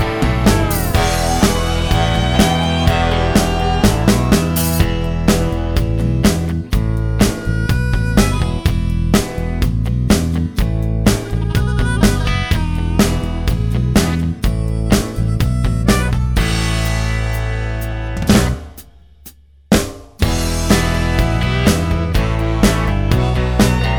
No Two Part Harmony Country (Male) 3:59 Buy £1.50